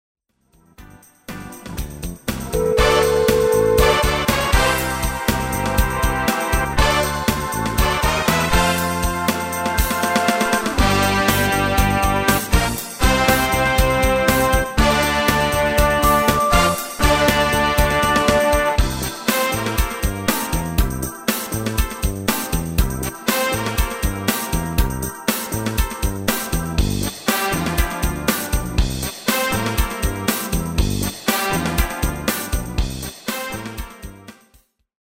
Demo/Koop midifile
Genre: R&B / Soul / Funk
- Géén vocal harmony tracks
Demo's zijn eigen opnames van onze digitale arrangementen.